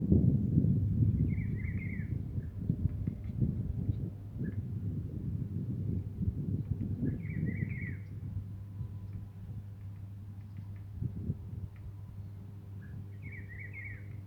Juan Chiviro (Cyclarhis gujanensis)
Nombre en inglés: Rufous-browed Peppershrike
Condición: Silvestre
Certeza: Observada, Vocalización Grabada